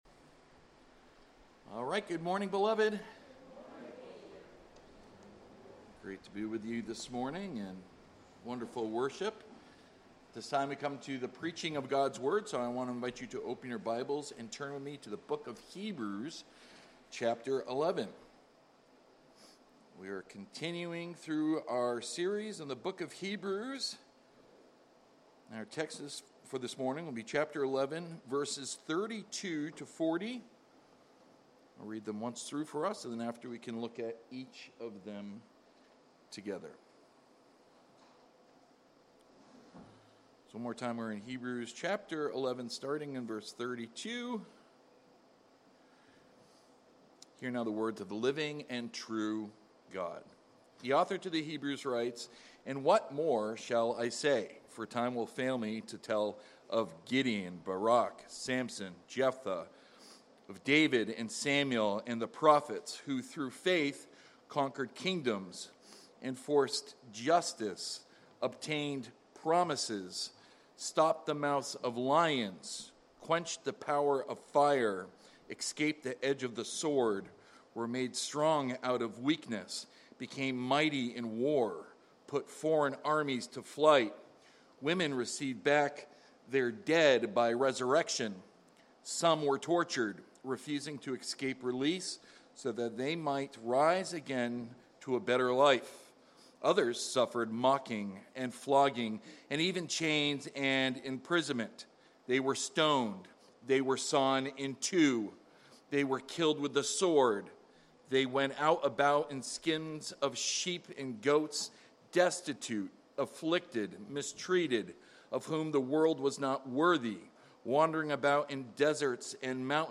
Sermons by At the Cross